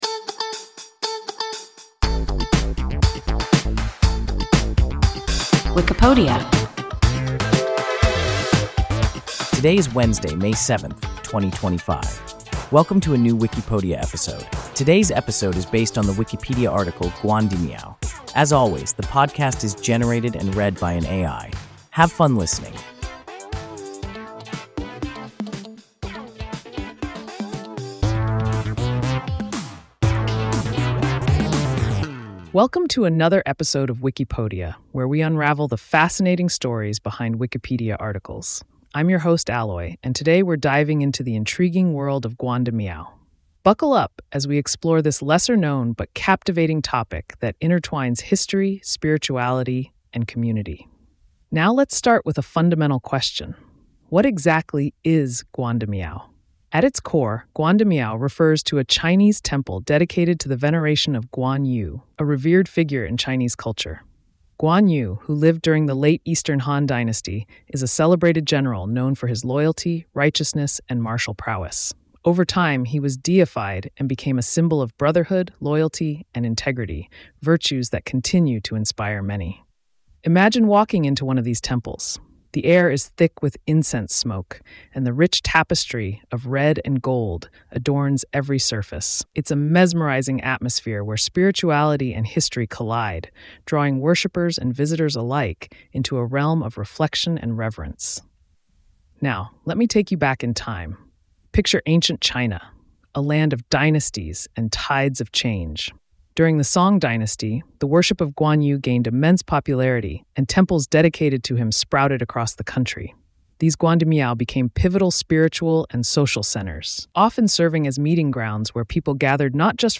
Guandimiao – WIKIPODIA – ein KI Podcast